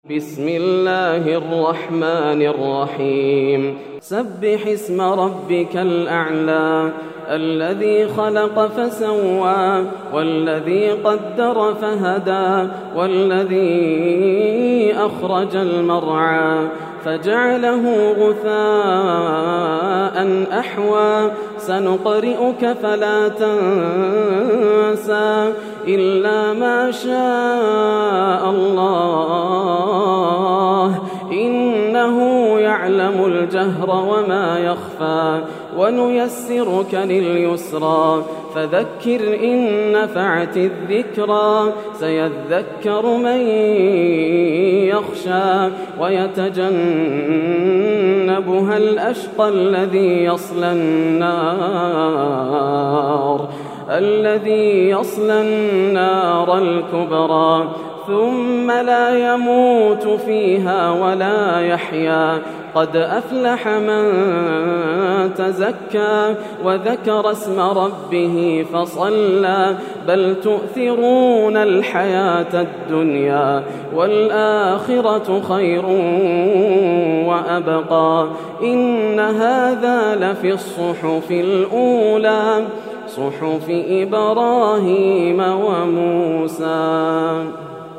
سورة الأعلى > السور المكتملة > رمضان 1431هـ > التراويح - تلاوات ياسر الدوسري